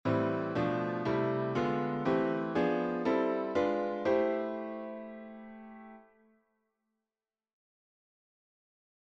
Improvisation Piano Jazz
Accord iim7